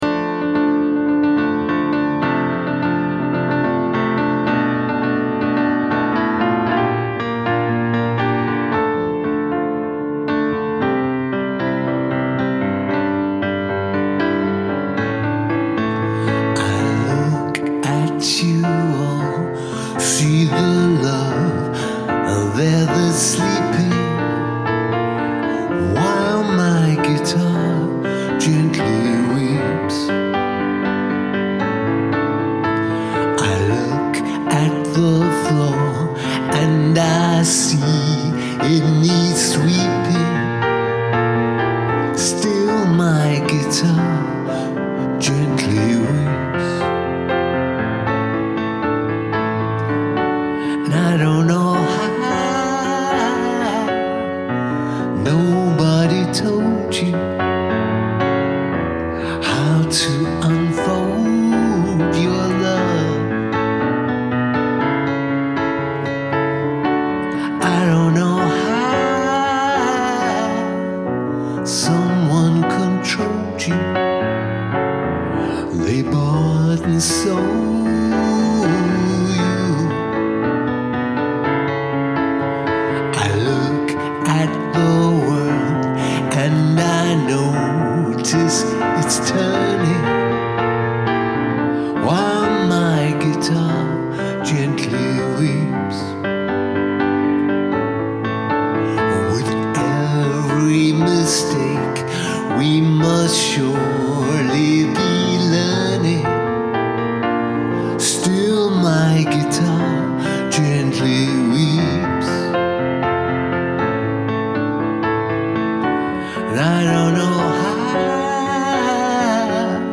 Oh my! This is so cool and a bit theatrical!